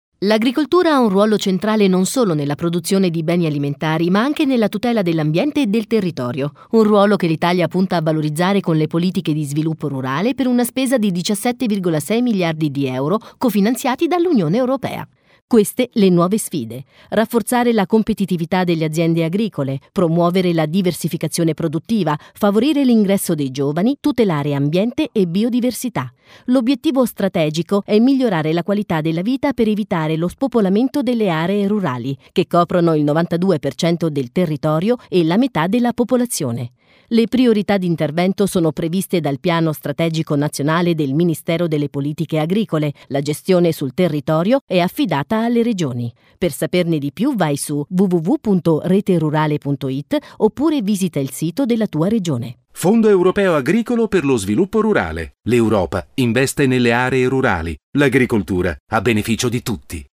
Messaggi radiofonici